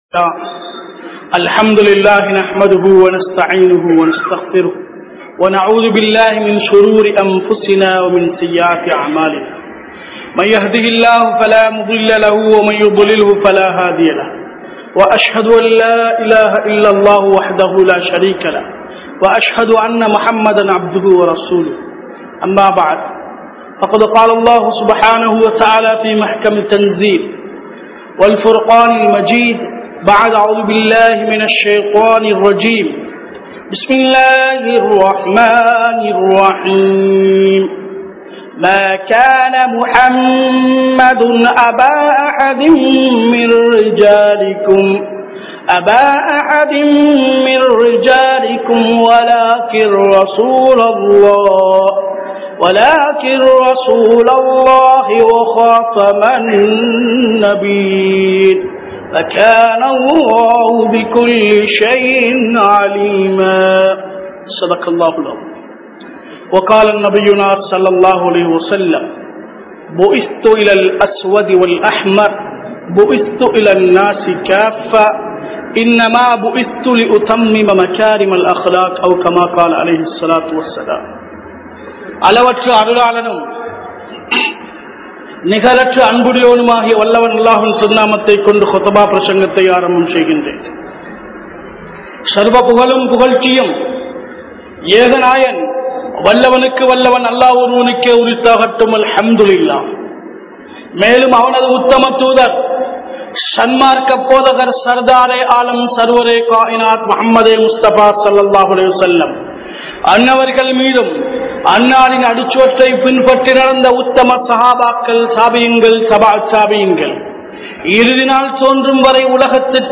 Nabi(SAW)Avarhalin Anpu (நபி(ஸல்)அவர்களின் அன்பு) | Audio Bayans | All Ceylon Muslim Youth Community | Addalaichenai